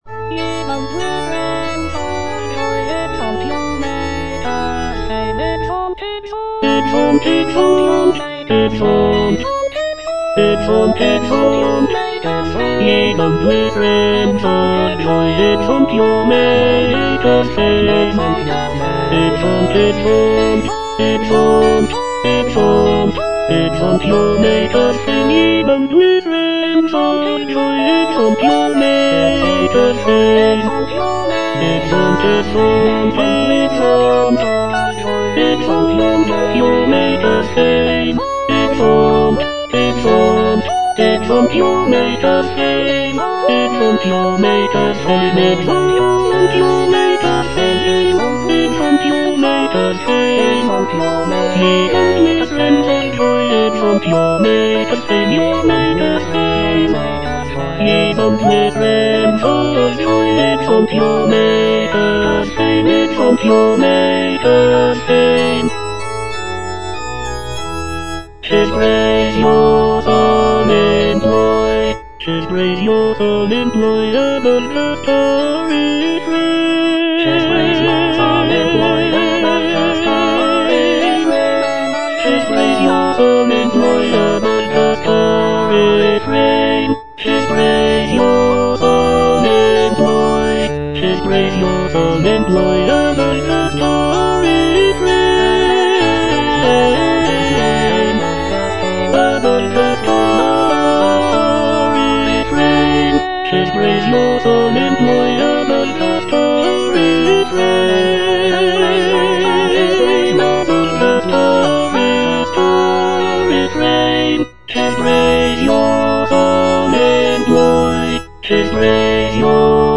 (A = 415 Hz)
Tenor (Emphasised voice and other voices) Ads stop
sacred choral work